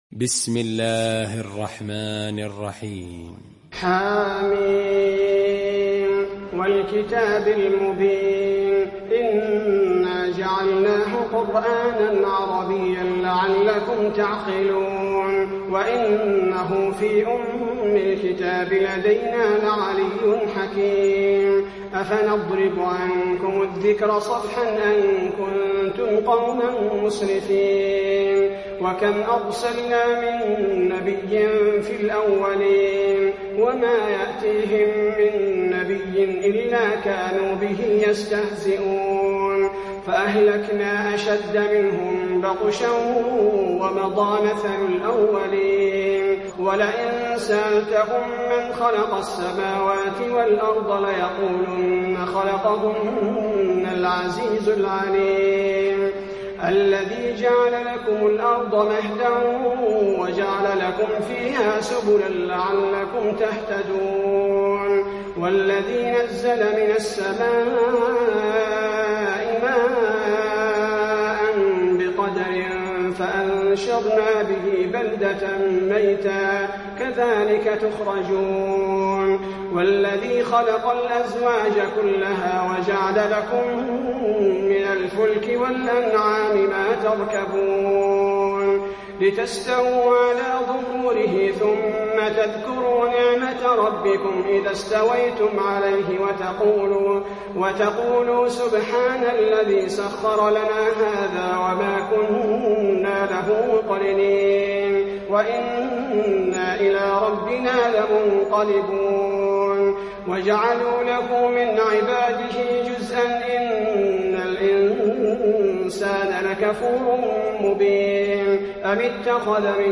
المكان: المسجد النبوي الزخرف